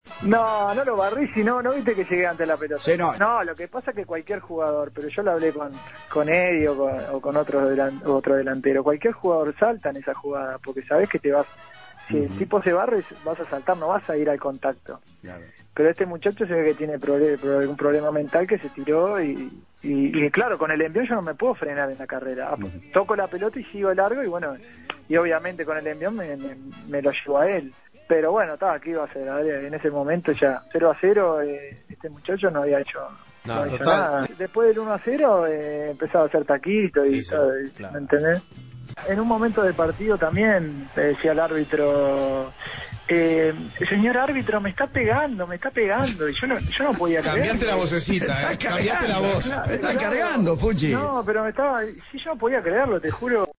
Jorge Fucile, una de las figuras más destacadas en Sudáfrica 2010, conversó con la Segunda Mañana de En Perspectiva.